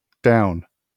IPA/daʊn/, SAMPA/daUn/
wymowa amerykańska?/i